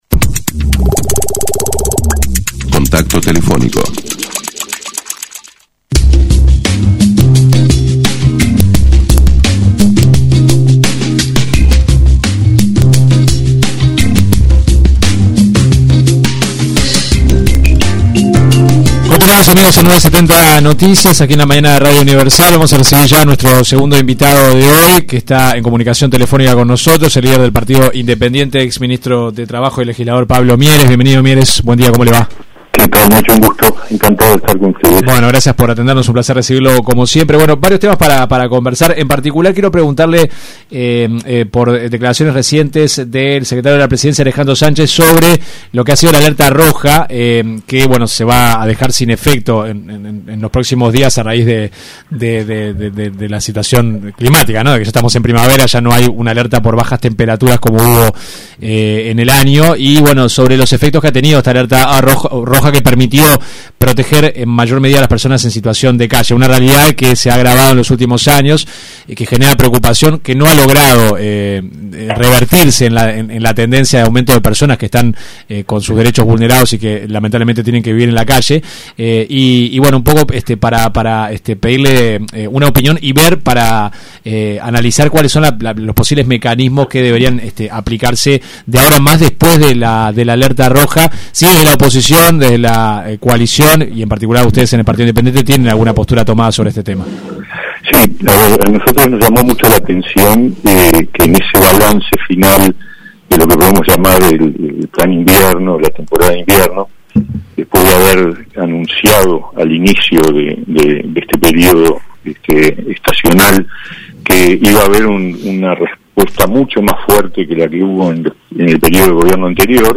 El líder del Partido Independiente, Pablo Mieres, criticó en una entrevista con 970 Noticias, la «estrategia» por parte del Gobierno, que emitió una alerta roja ara las personas en situación de calle por la ola de frío que azotó al país durante el invierno.